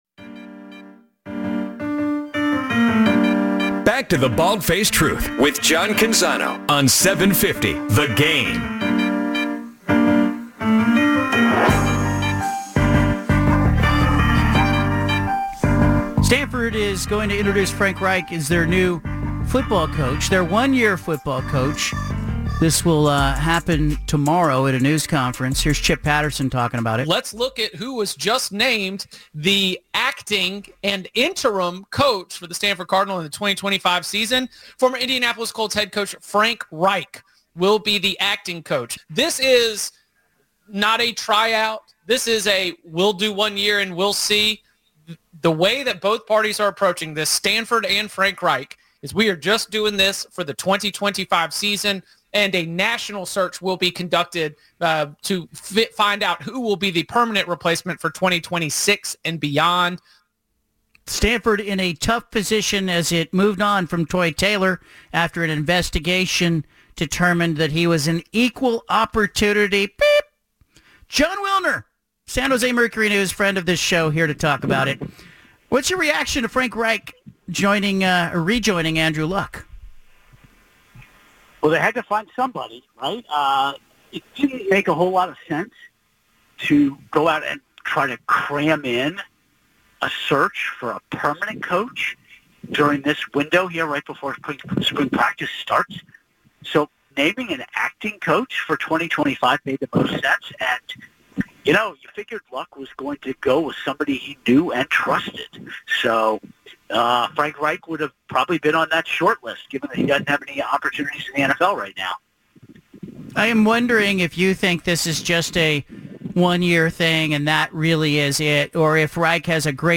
BFT Interview